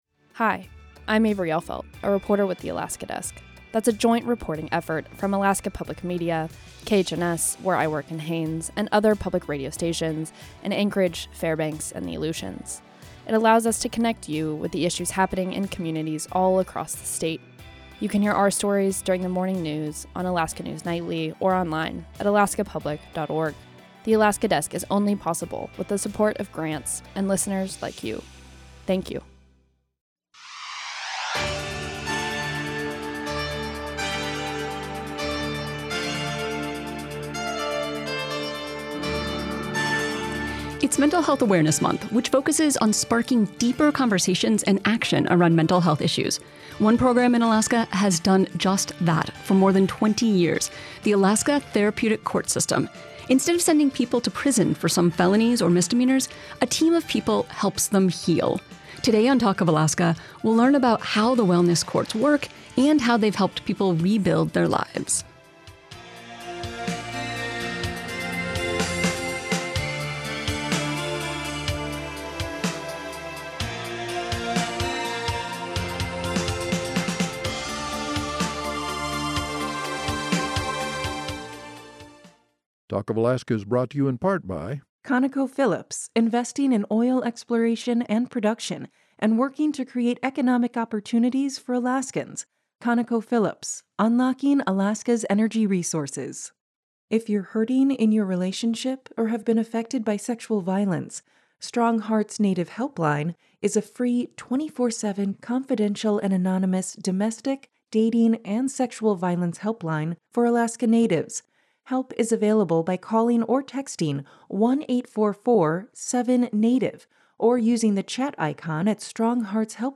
Instead of going to prison, some people choose to participate in the Alaska Therapeutic Court System where a team of people helps them heal. We’ll hear from people who have graduated from the program and people who run it.<